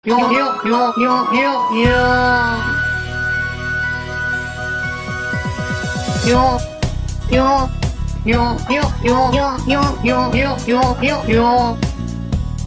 Bowling Sound Effects MP3 Download Free - Quick Sounds